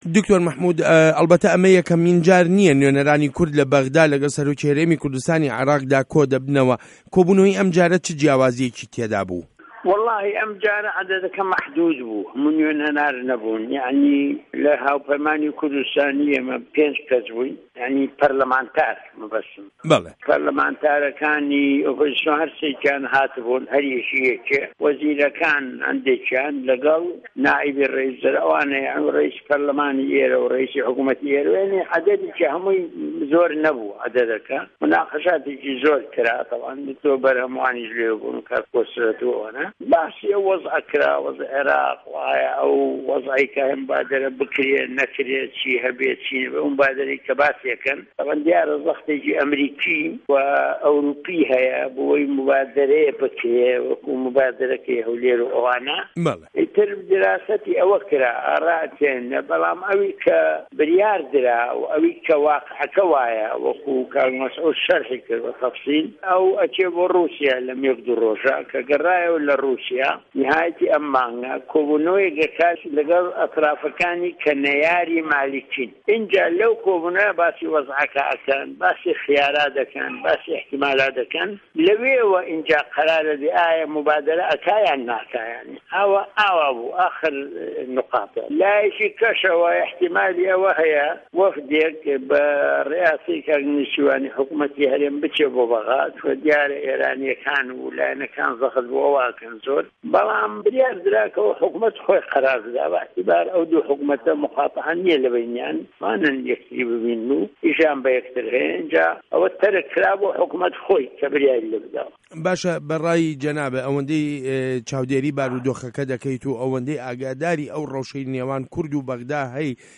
وتووێژ له‌گه‌ڵ دکتۆر مه‌حمود عوسمان